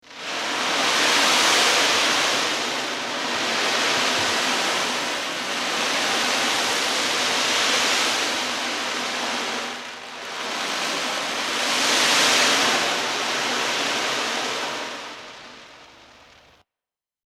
Ocean
Ocean je glasbilo/zvočilo na lesenem okvirju, ki je z obeh strani zaprto s kožno opno. V notranjosti ima semena, ki ob vodoravnem premikanju ustvarjajo zvok morja.